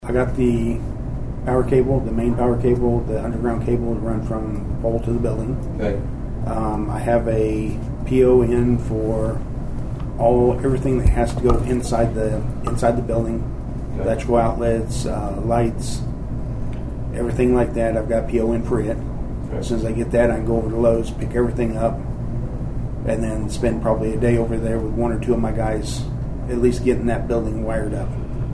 The Nowata County Commissioners met for a regularly scheduled meeting on Monday morning at the Nowata County Annex.
Chairman Paul Crupper talked about getting power to the tower.